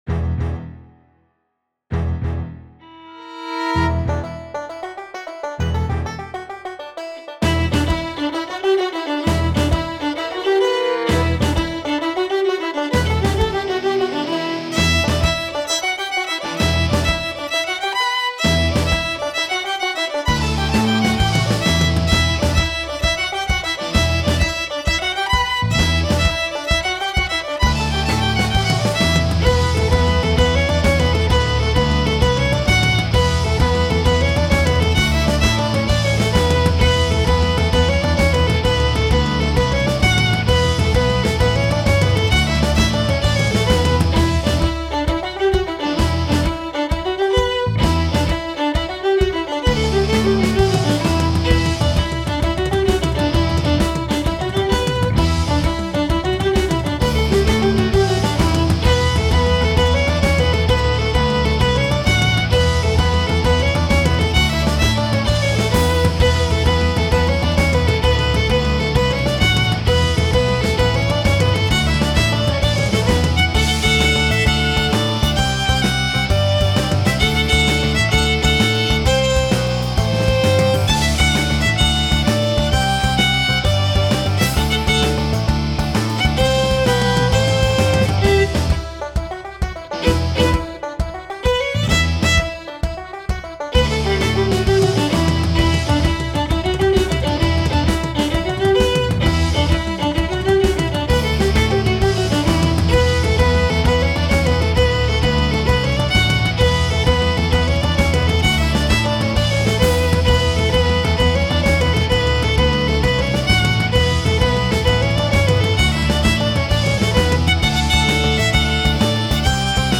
Recueil pour Violon